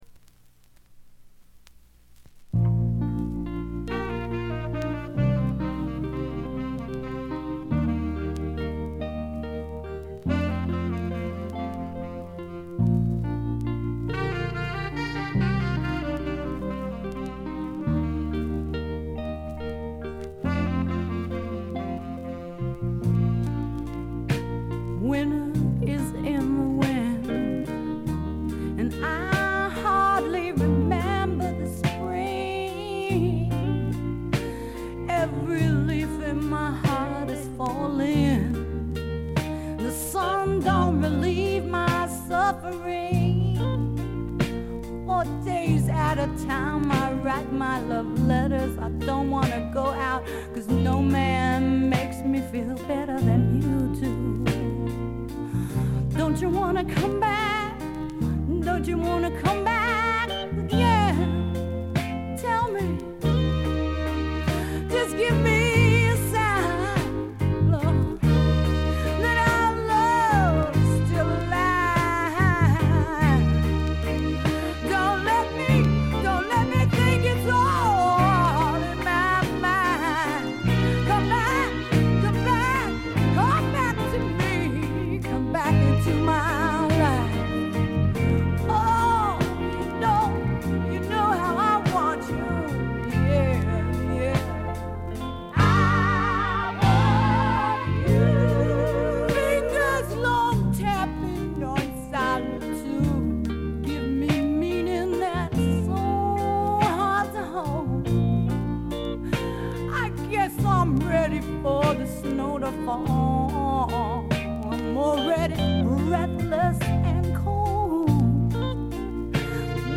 部分試聴ですがほとんどノイズ感無し。
ファンキーでタイト、全編でごきげんな演奏を繰り広げます。
試聴曲は現品からの取り込み音源です。